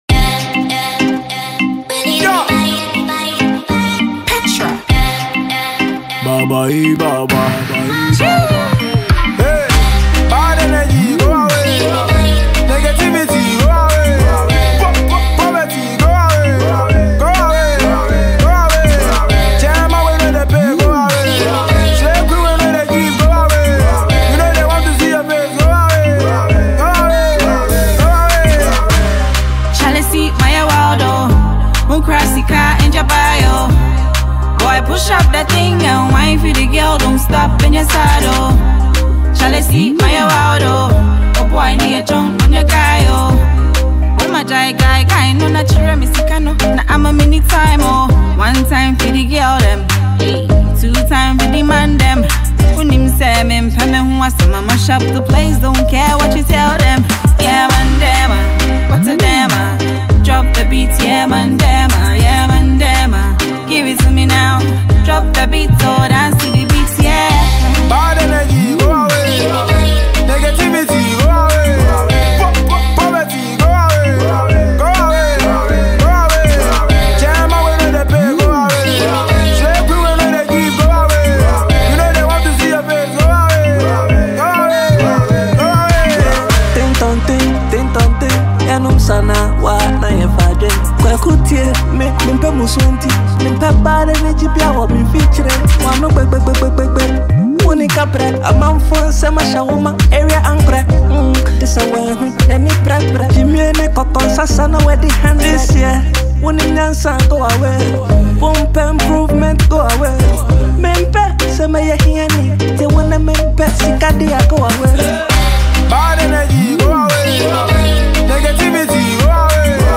Ghana Music
atmospheric